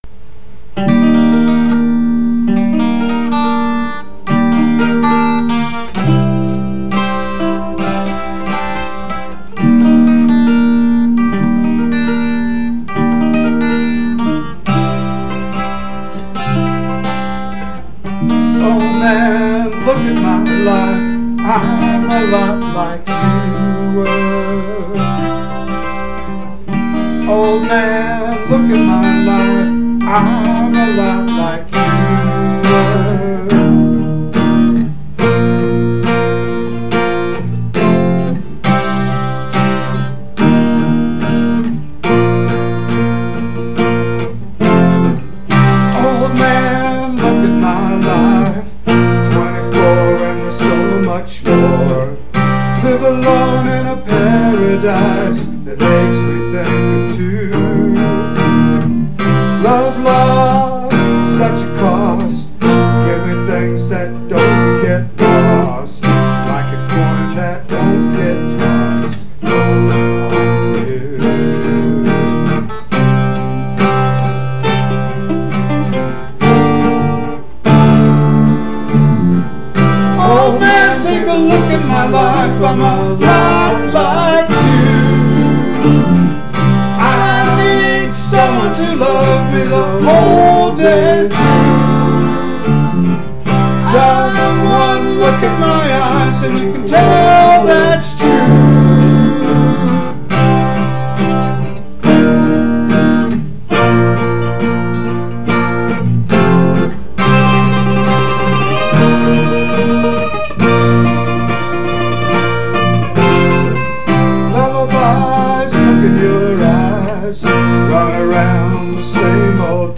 Vocals, 12-string acoustic guitar, mandolin
bass